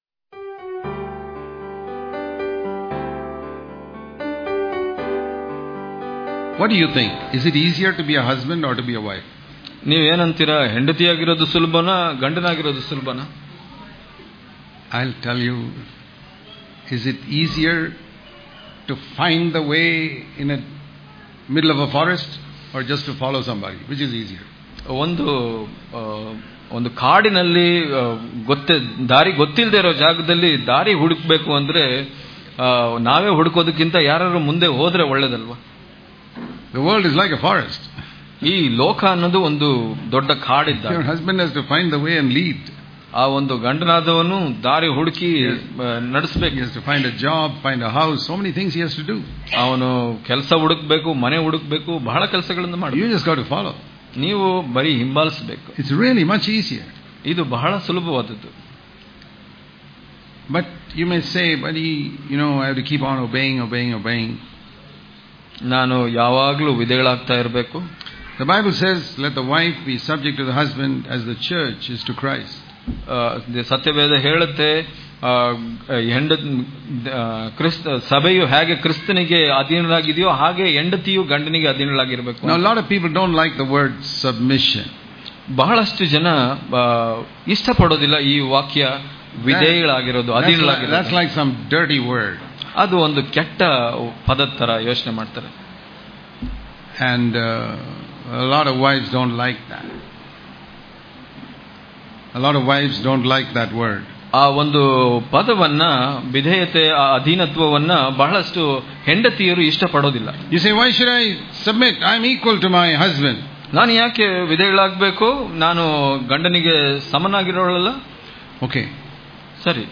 April 12 | Kannada Daily Devotion | Submission Is Very Important In The Family Daily Devotions